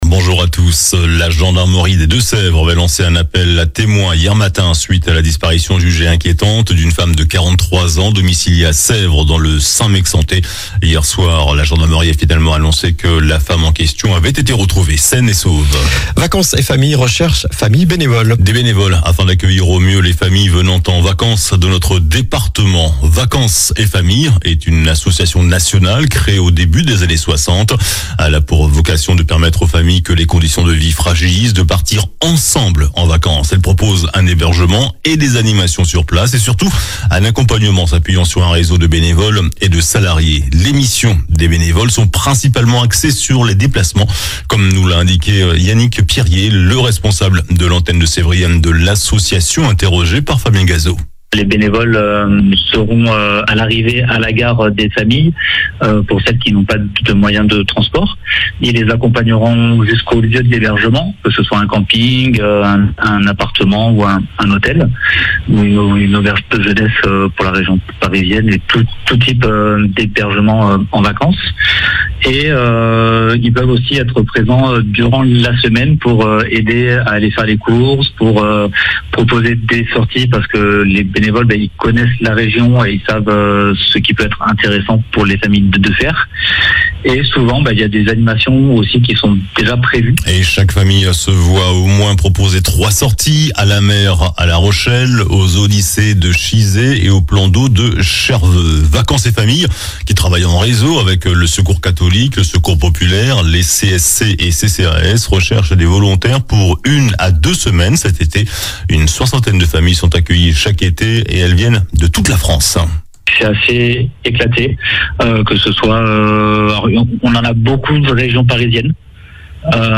Journal du samedi 8 juin (matin)